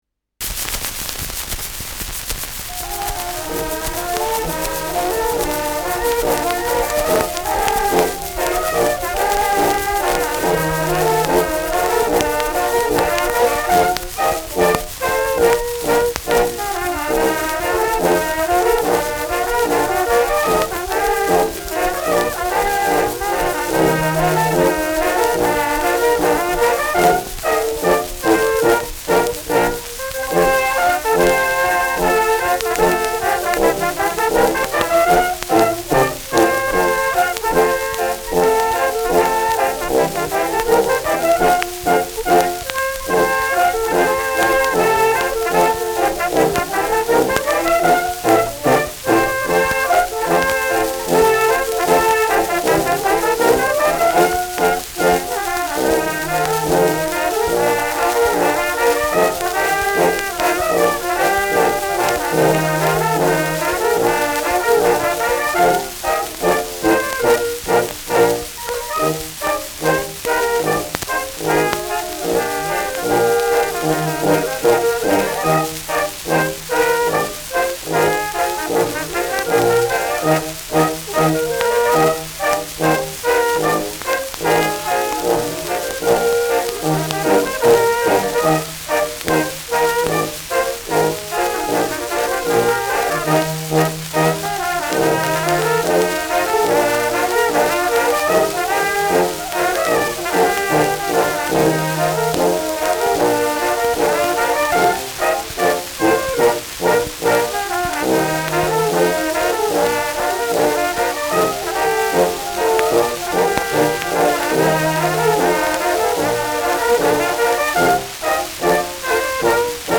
Schellackplatte